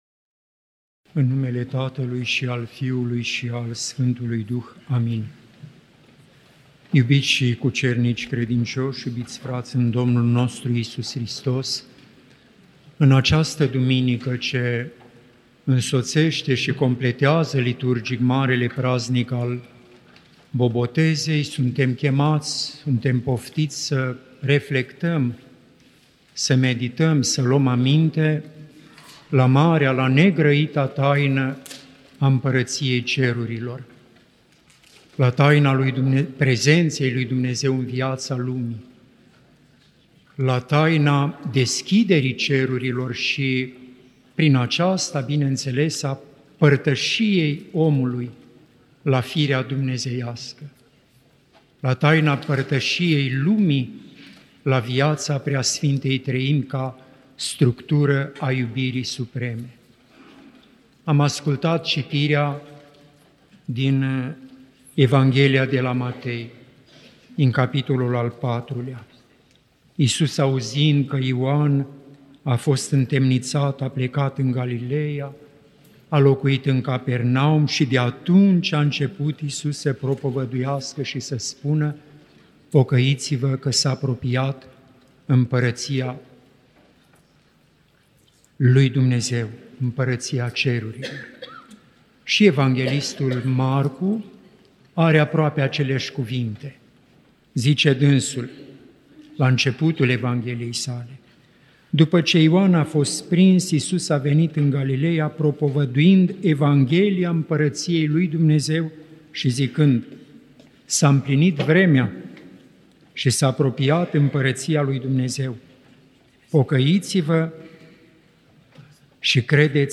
Cuvinte de învățătură